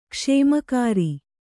♪ kṣēmakāri